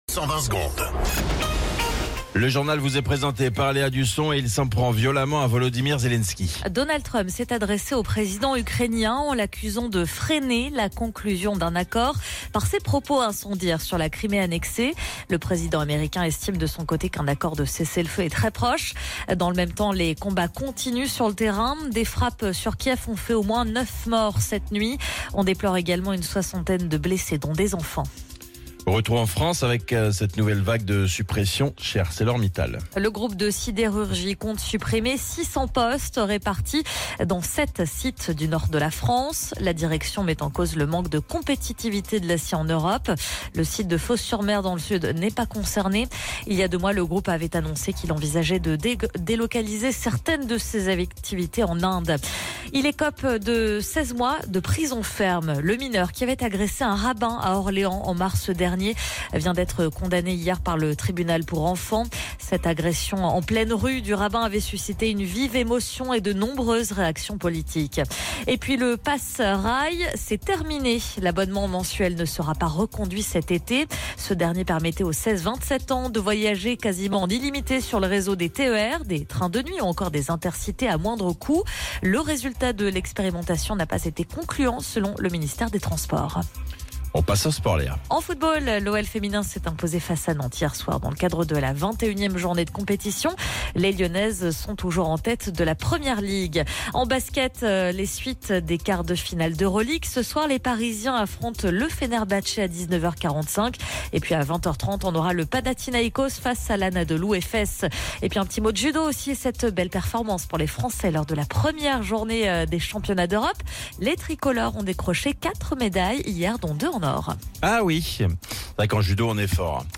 Flash Info National 24 Avril 2025 Du 24/04/2025 à 07h10 .